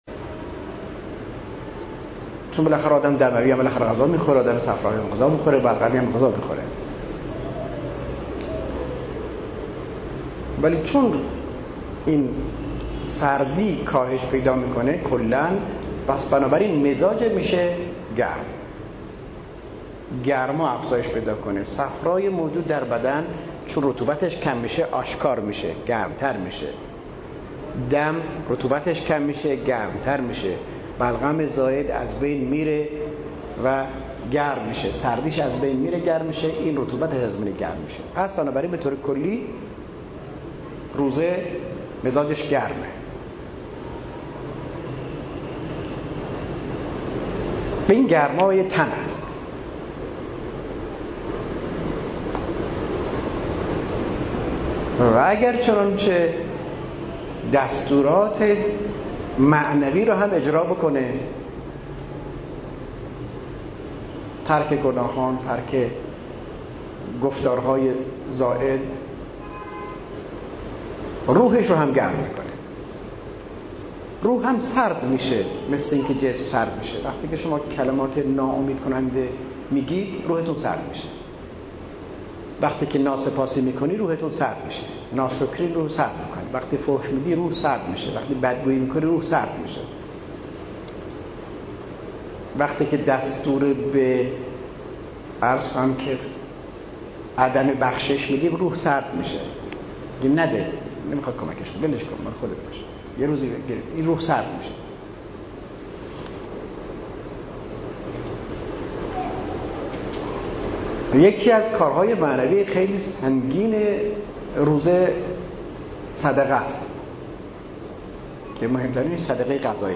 بحث تحلیل روزه از نگاه مزاج شناسی و نقش آن در درمان ، قم حسینیه موسی بن جعفر